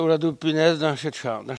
Patois